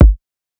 TS Kick_3.wav